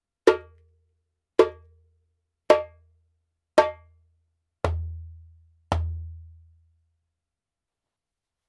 軽量ボディに、明るくシャープな音のパキスタンの薄めのヤギ皮。高音の切れ抜群。薄めの皮でも中音が面白いように胴鳴りします。
ジャンベ音 Djembe Sound